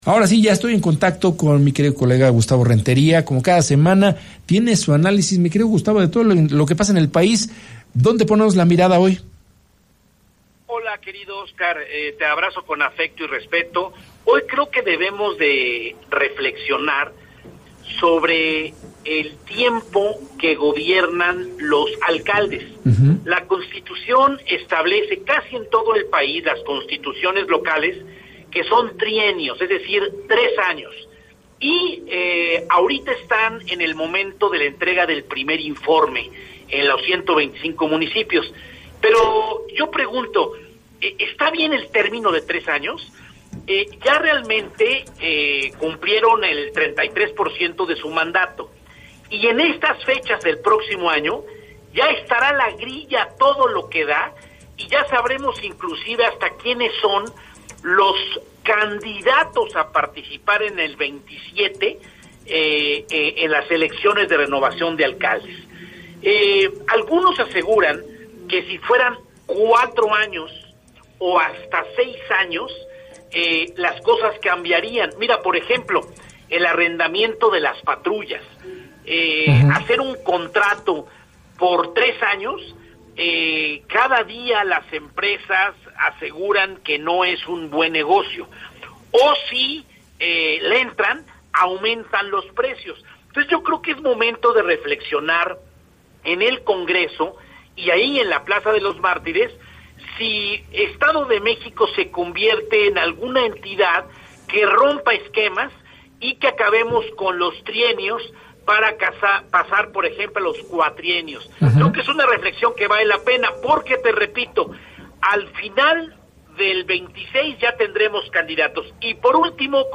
comentarista político